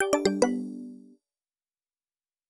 Special & Powerup (37).wav